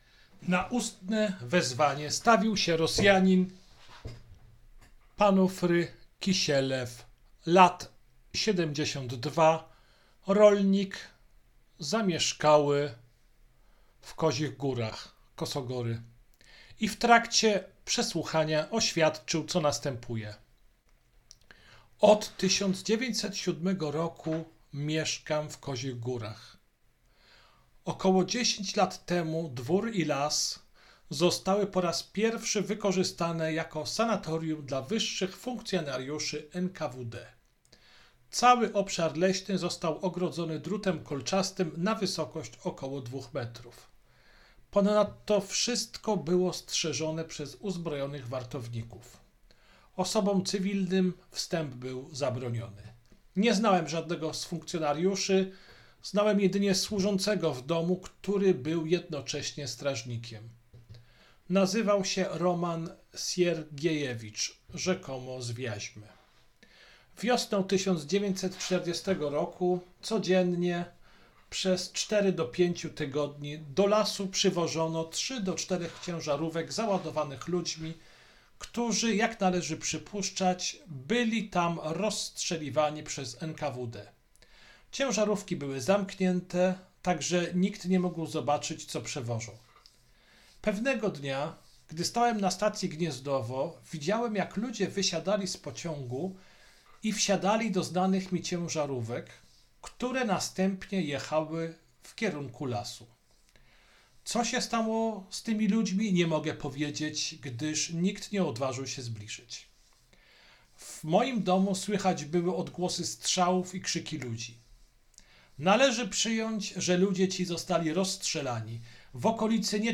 Kategoria: zeznanie świadka